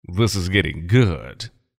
voice_tier2_gettinggood.mp3